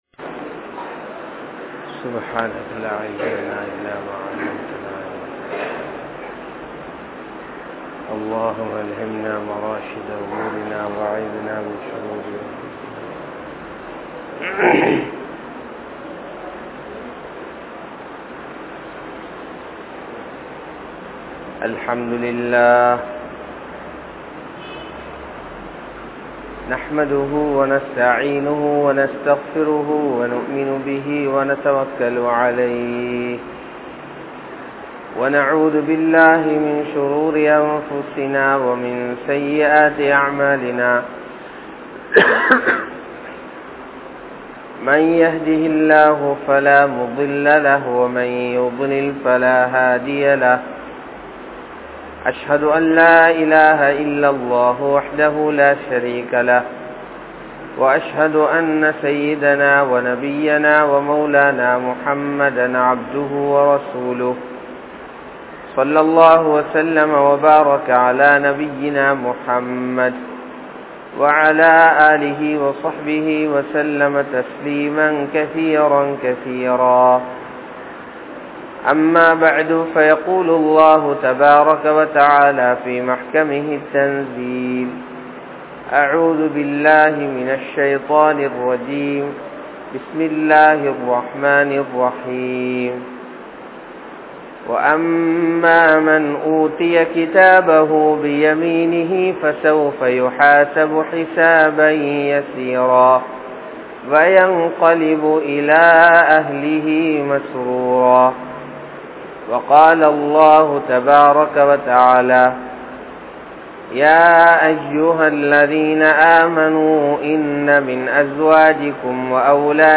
Nikkah Seivathan Noakkam (நிக்காஹ் செய்வதன் நோக்கம்) | Audio Bayans | All Ceylon Muslim Youth Community | Addalaichenai